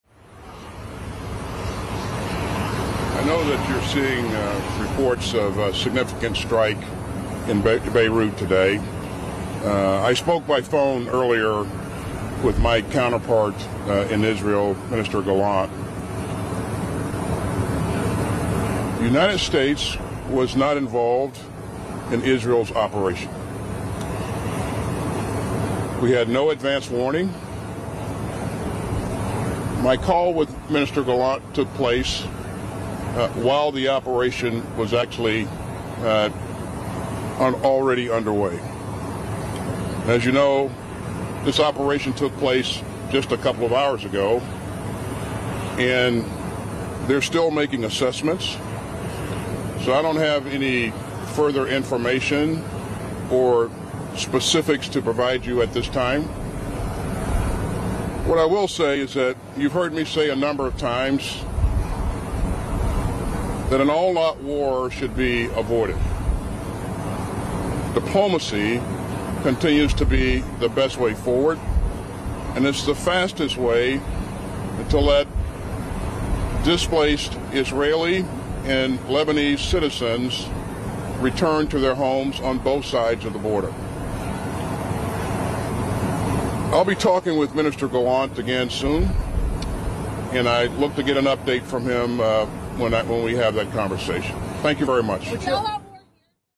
Tarmac Statement on Israel Air Strikes Against Hezbollah in Lebanon
delivered 27 September 2024, Joint Base Andrews, Prince George's County, Maryland
lloydaustintarmacisraeliairstrike.mp3